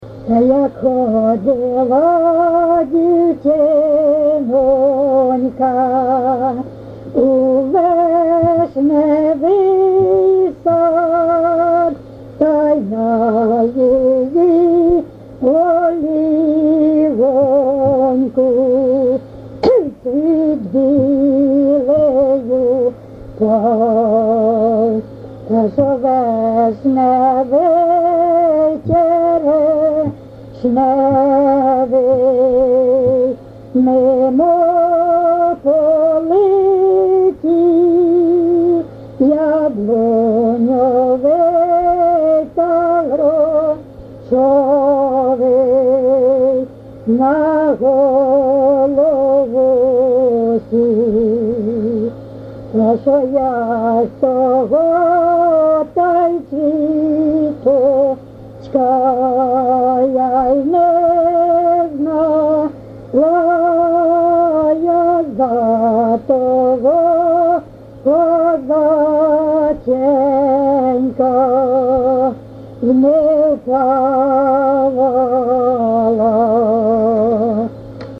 ЖанрВесільні
Місце записус. Коржі, Роменський район, Сумська обл., Україна, Слобожанщина